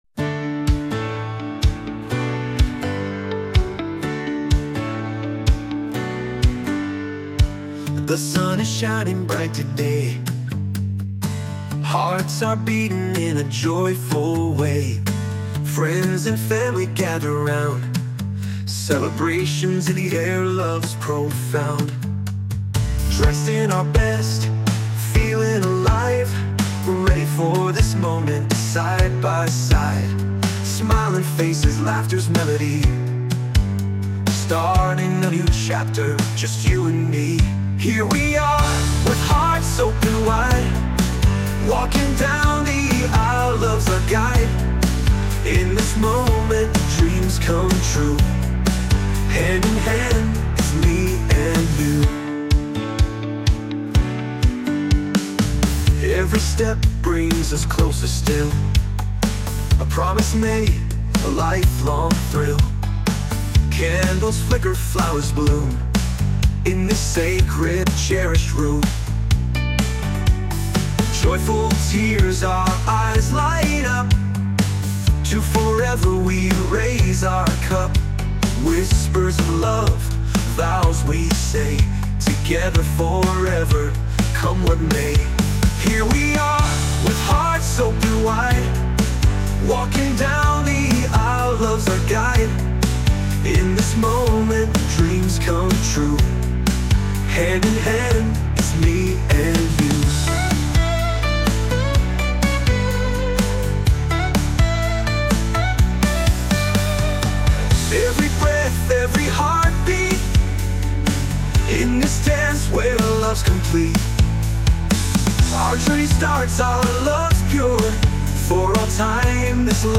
男性ボーカル洋楽 男性ボーカルオープニングムービーエンドロール
男性ボーカル（洋楽・英語）曲です。